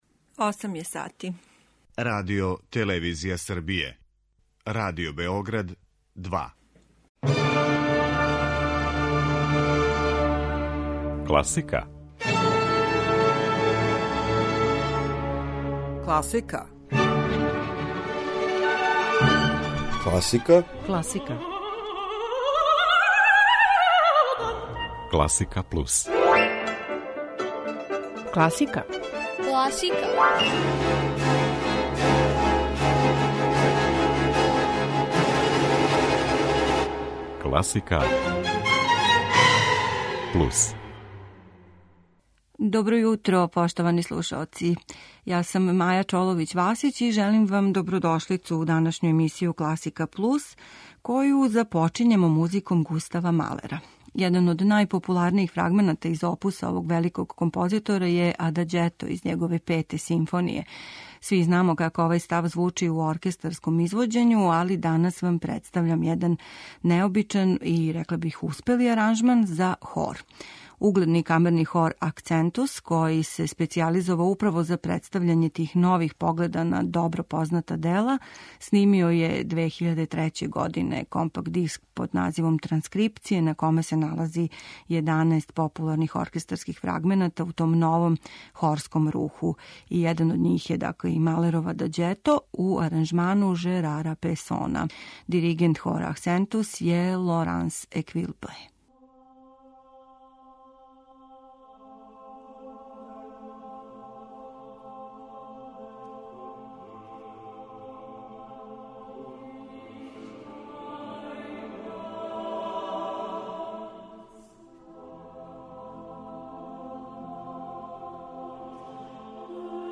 слушамо у необичном аранжману